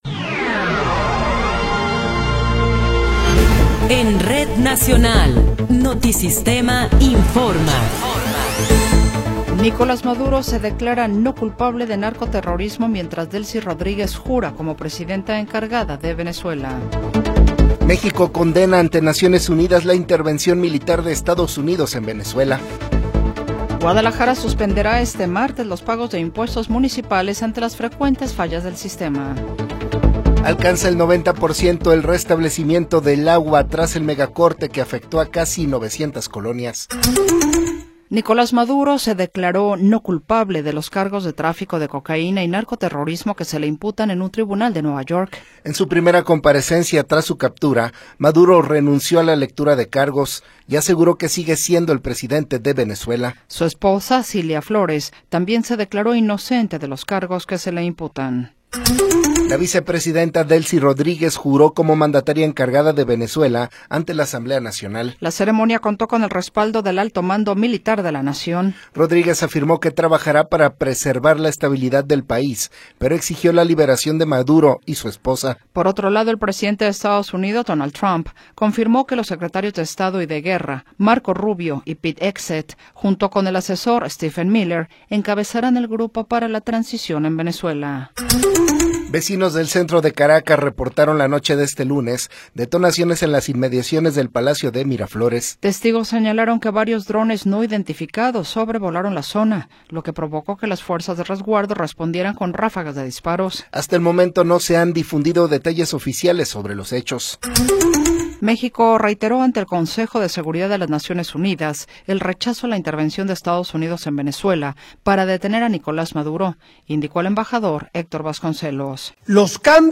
Noticiero 20 hrs. – 5 de Enero de 2026